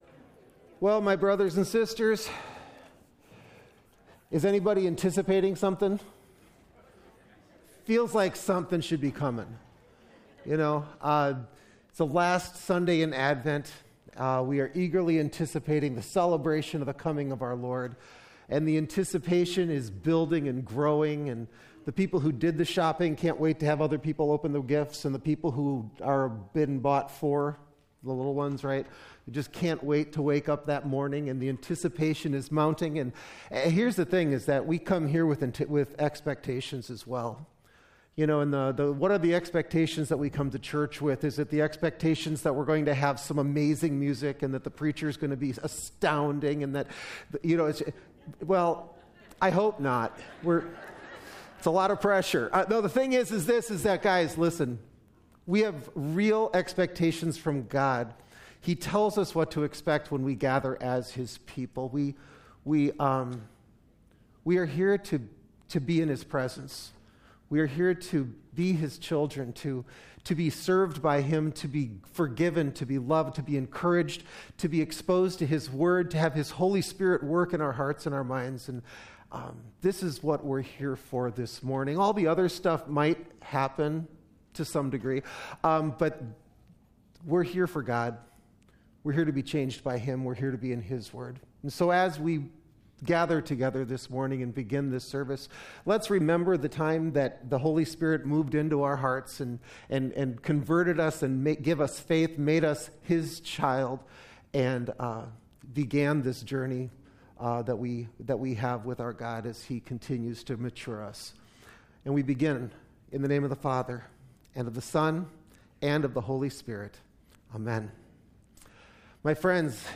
12-19-21 Worship Service